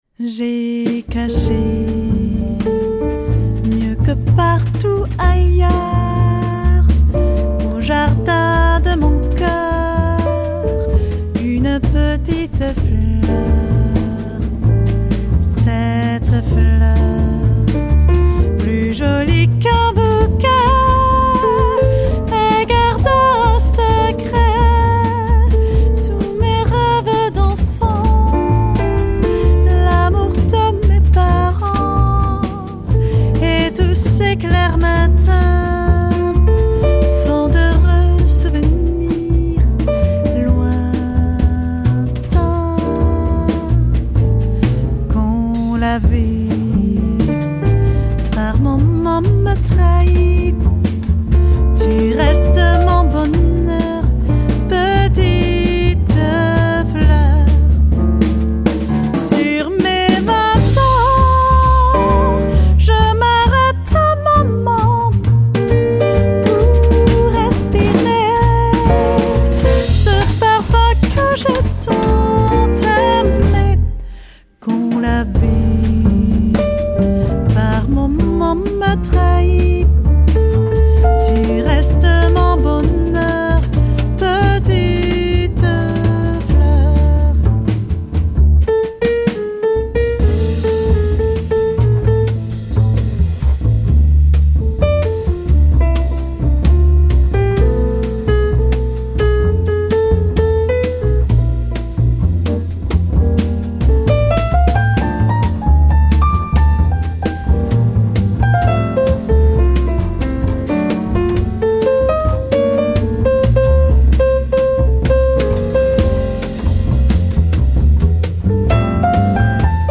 elegantes hauptstadt-quartett